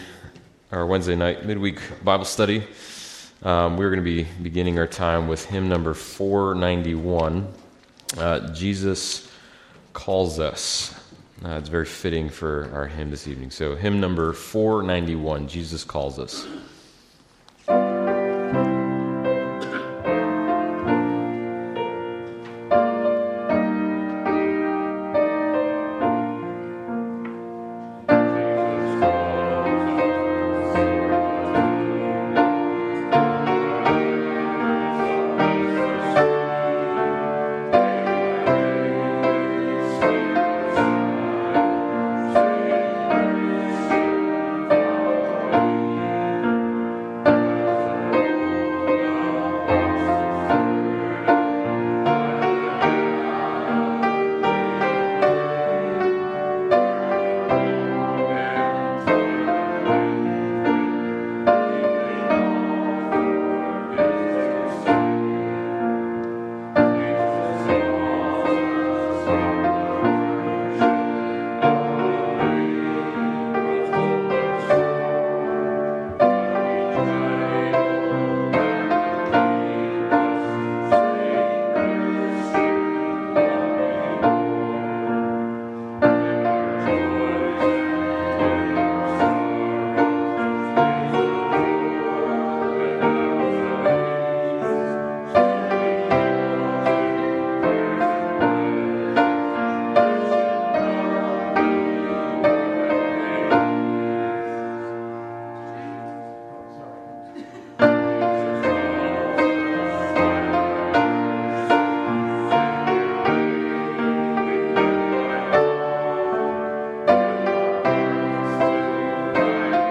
Mark Passage: Mark 1 Service Type: Wednesday Devotional « WMBS